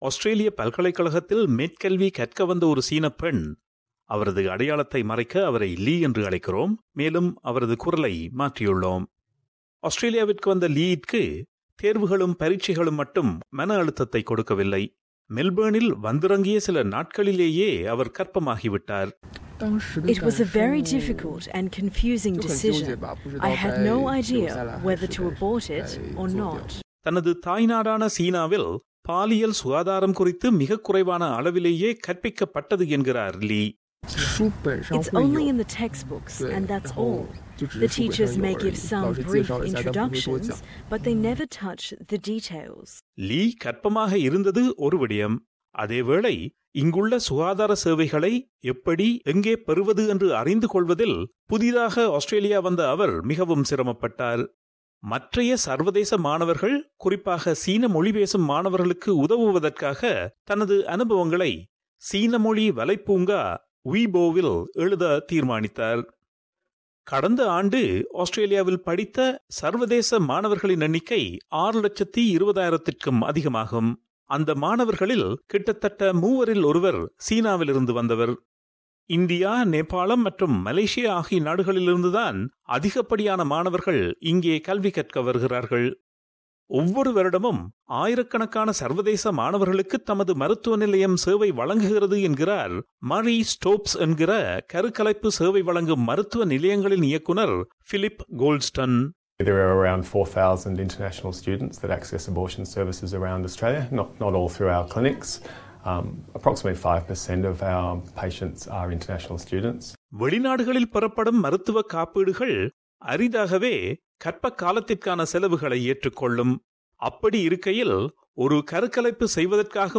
எழுதிய செய்தி விவரணத்தைத் தமிழில் தருகிறார்